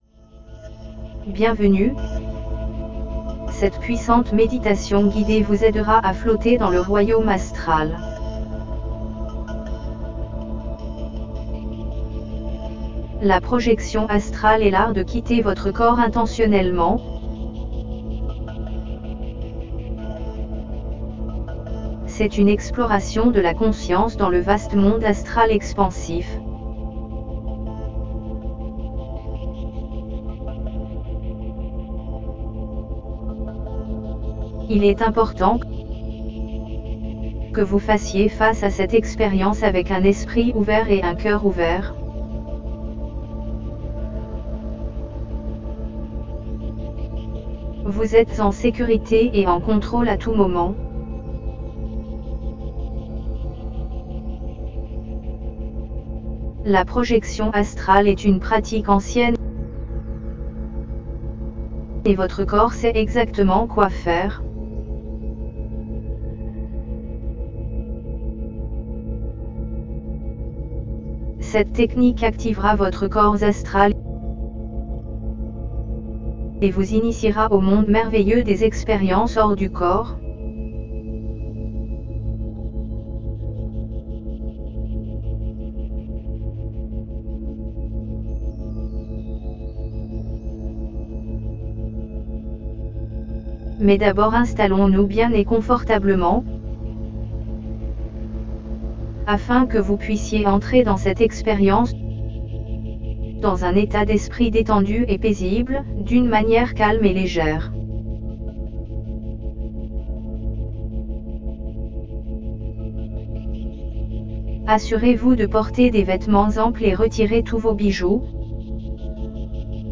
Ce guide de méditation par projection astrale vous aidera avec la puissante technique du «ballon flottant».
Nous vous recommandons d'utiliser des écouteurs / bouchons d'oreilles pour une expérience optimale, car la méditation est intégrée avec des battements binauraux thêta de 4,5 Hz.